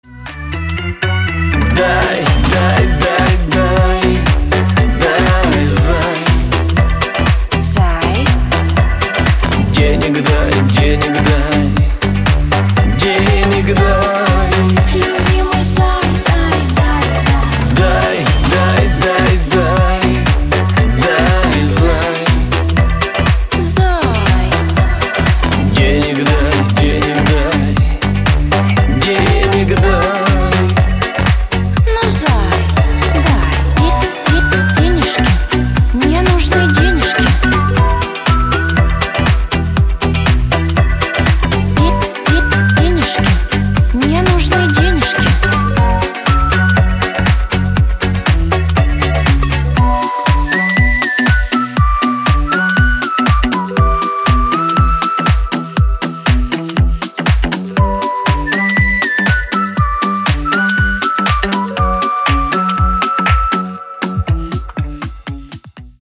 • Качество: 320, Stereo
свист
мужской вокал
веселые
женский голос
русский шансон
шуточные